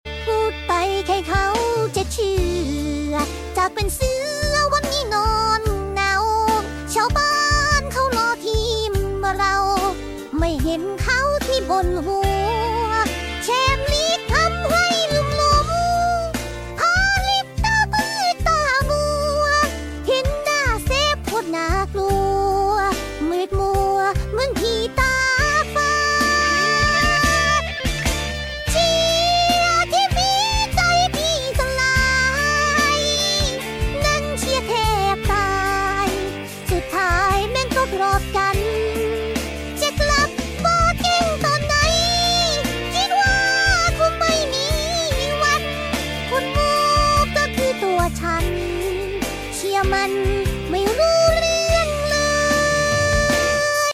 Ai Cover